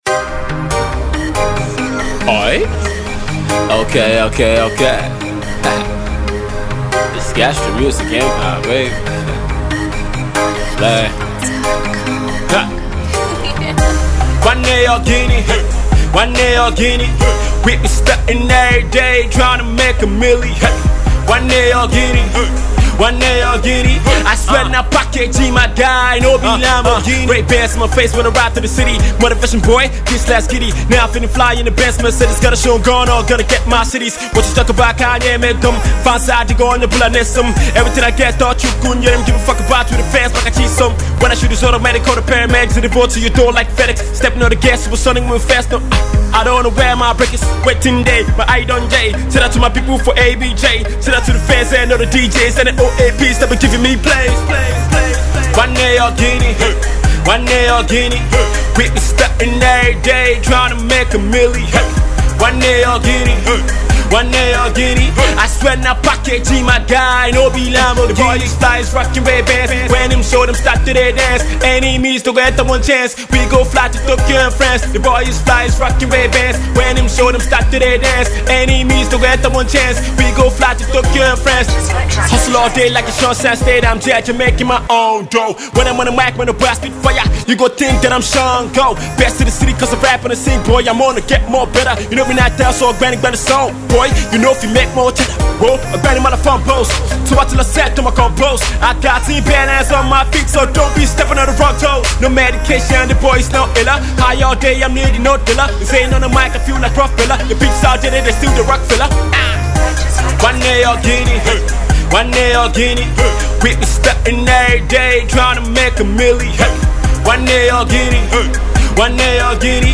solid Rap tune for the streets